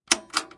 button-open.ogg